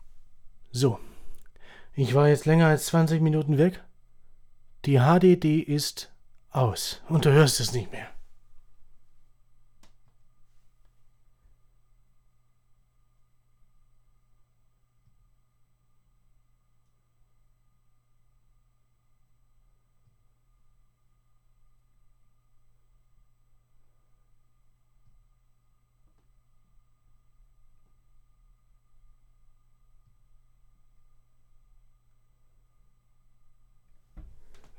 Neue Lüfter eingebaut - aber trotzdem ist ein Rauschen zu vernehmen
Ich war mehr als 20 Minuten weg und die HDD ist aus.
Aufgenommen wurde mit 45 Dezibel. Anhänge HDD aus.wav HDD aus.wav 5,9 MB Ryzen 7 5700G | RTX 3050 MSI VENTUS OC Edition | Asus B550-E-Gaming | Seasonic TX-750 | Dark Rock 4 | 16GB G.Skill Ripjaws (3200Mhz) | Pioneer BDR-S12XLT